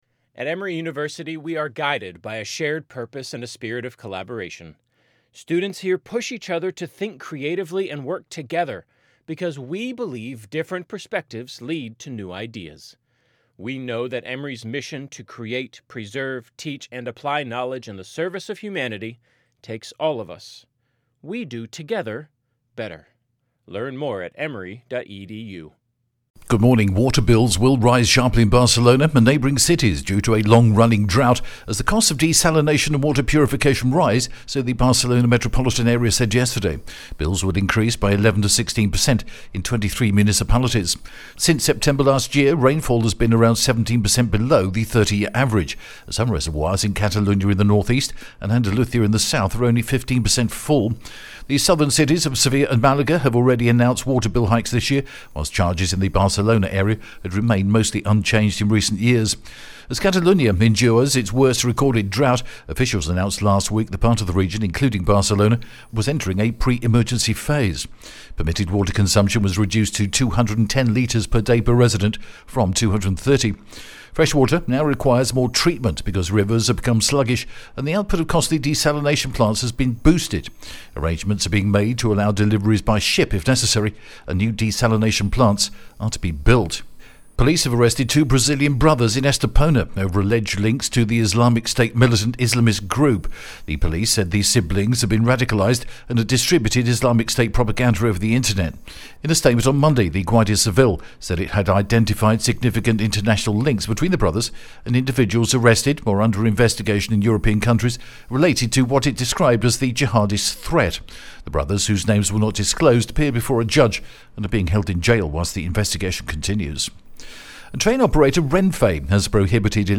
The latest Spanish news headlines in English: 29th November 2023